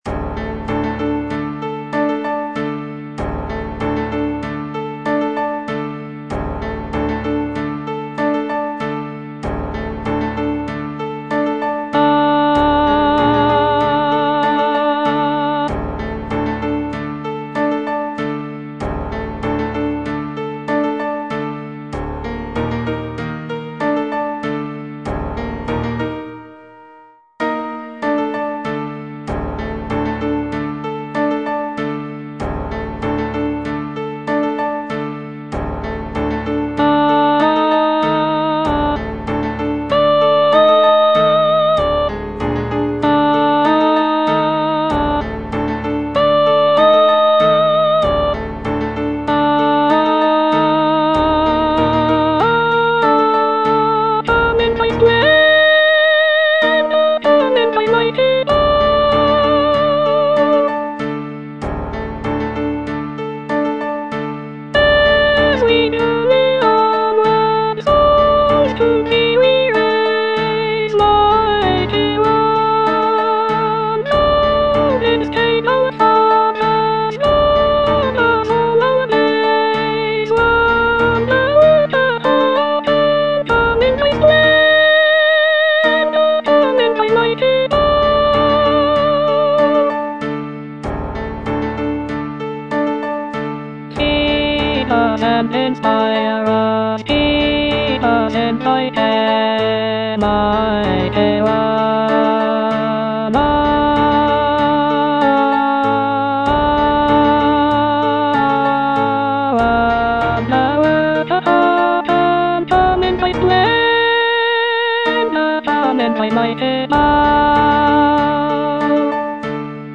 The piece is characterized by its lush harmonies and evocative melodies, reflecting Holst's interest in Indian philosophy and culture.